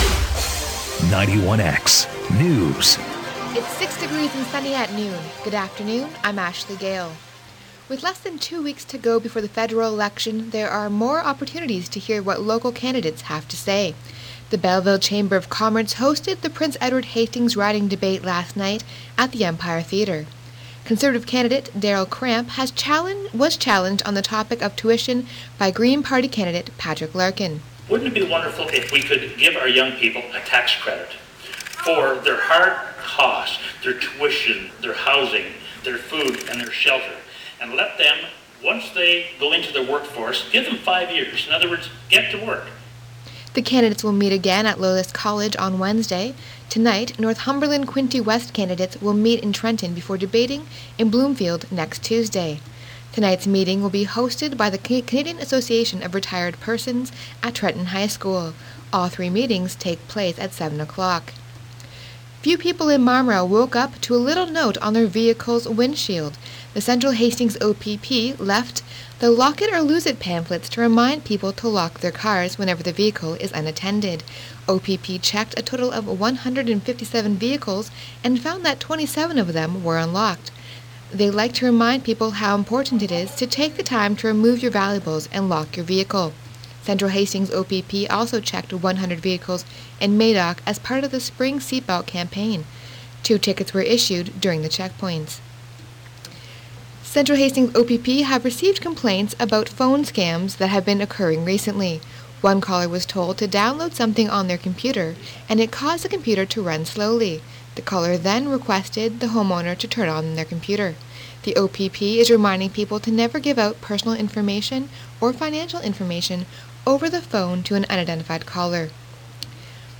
Quinte's Most Comprehensive News Source